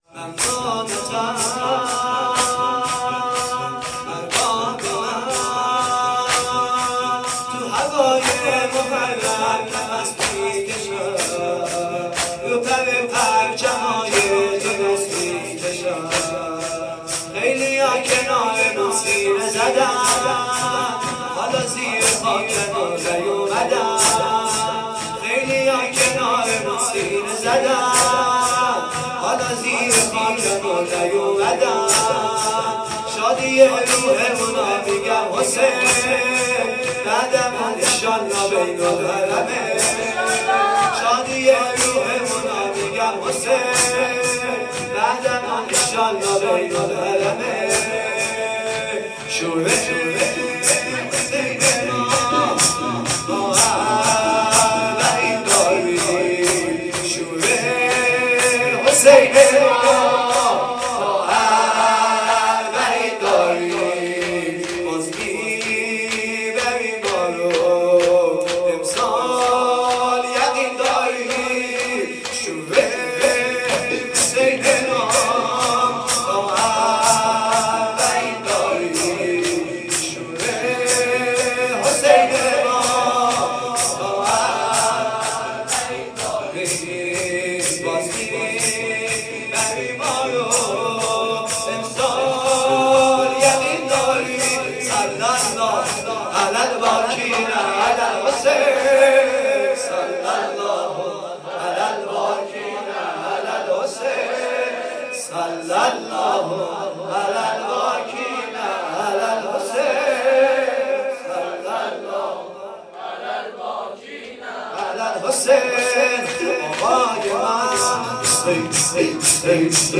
شب ۹ صفر ۹۷
شور